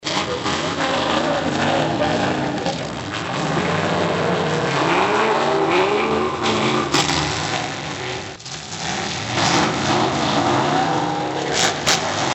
The Lyon County Fair came to a smashing conclusion Saturday night with the annual demolition derby.
demo-derby-1.mp3